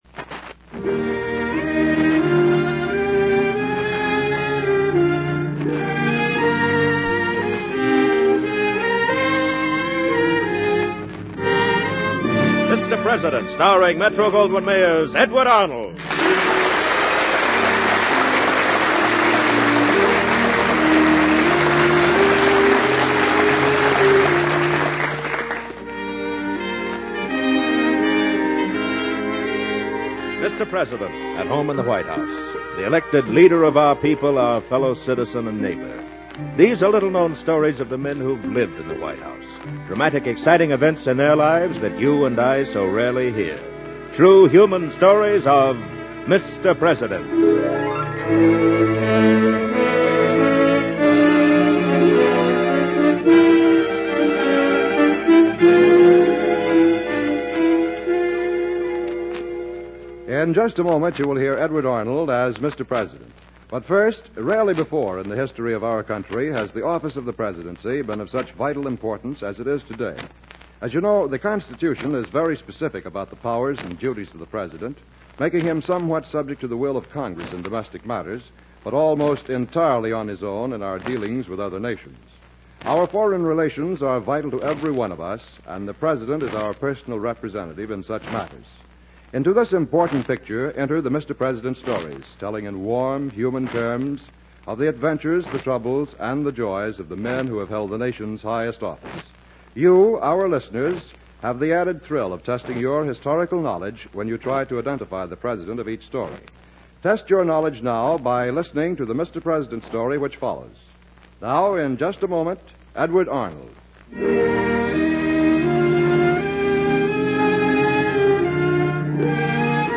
Mr. President, Starring Edward Arnold